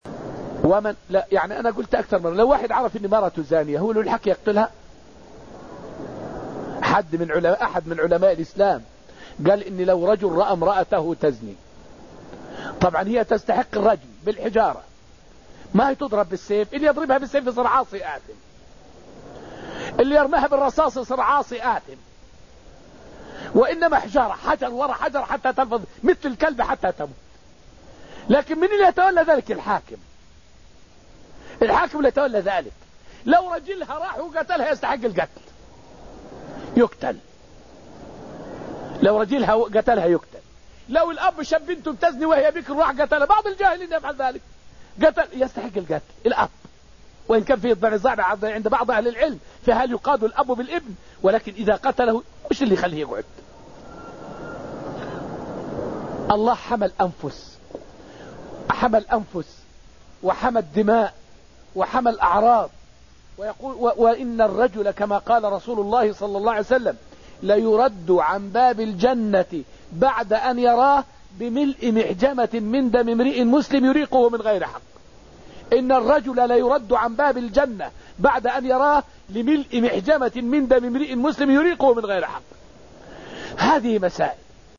فائدة من الدرس الثالث والعشرون من دروس تفسير سورة الحديد والتي ألقيت في المسجد النبوي الشريف حماية الشرع للدماء والأنفس والأعراض.